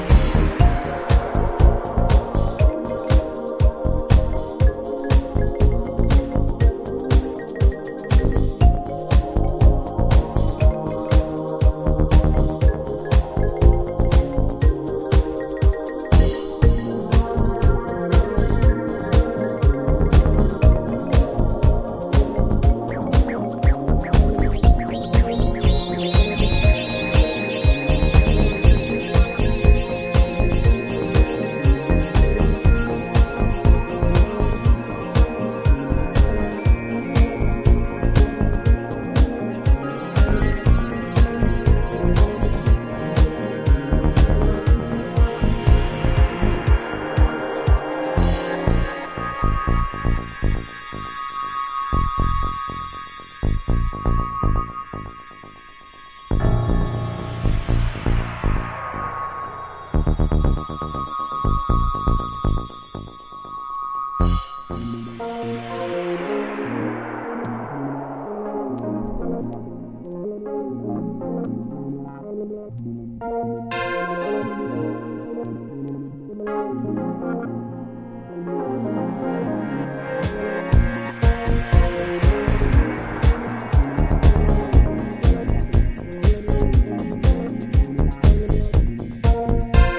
バレアリック・テイストの軸に淡く幻想的な情景が浮かぶチル・アウト系ベスト・シングル。